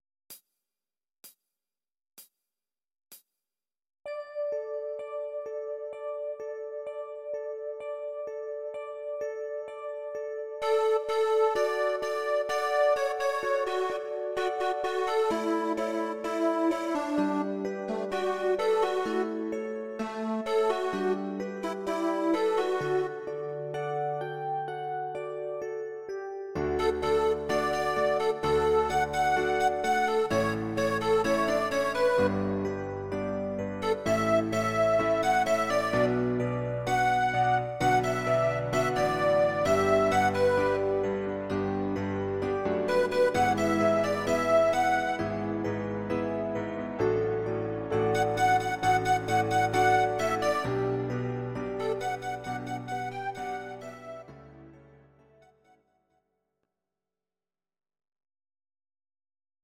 Audio Recordings based on Midi-files
Our Suggestions, Pop, Rock, 2010s